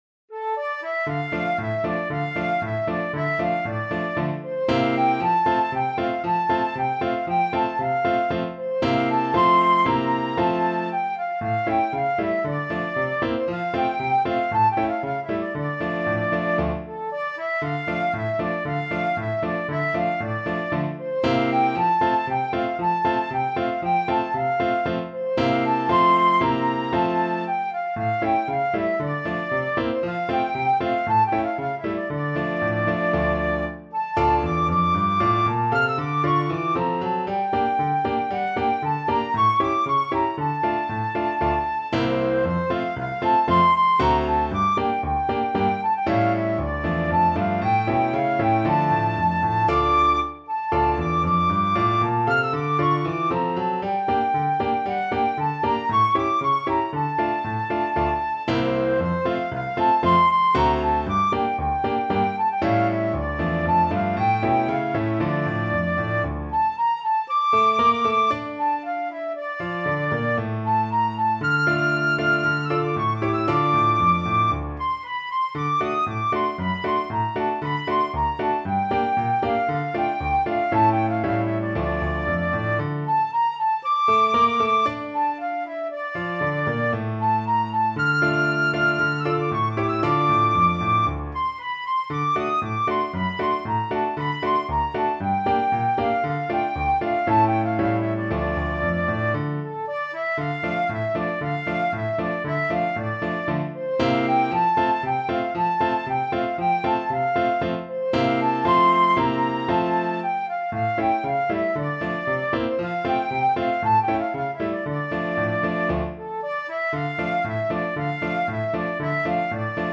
Free Sheet music for Flute
Flute
D minor (Sounding Pitch) (View more D minor Music for Flute )
Allegro =c.116 (View more music marked Allegro)
2/4 (View more 2/4 Music)
Traditional (View more Traditional Flute Music)